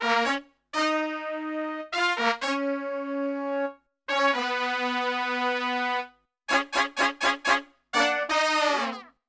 FUNK4 EBM.wav